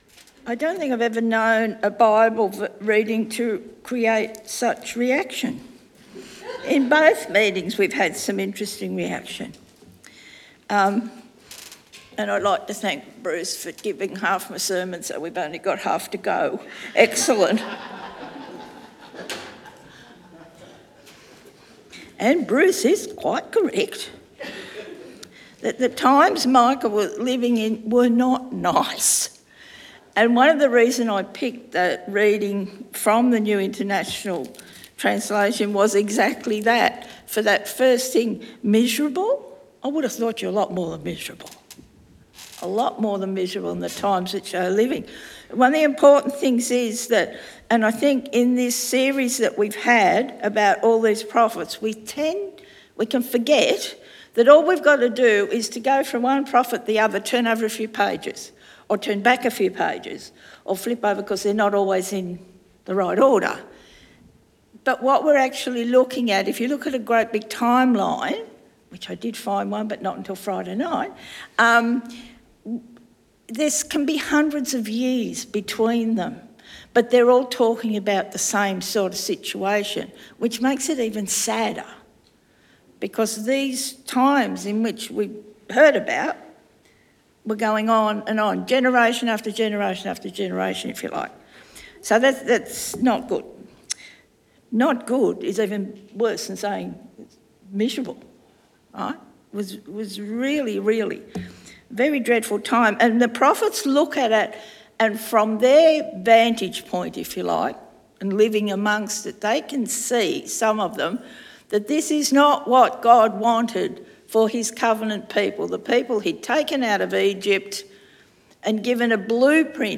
Sermon Podcasts Return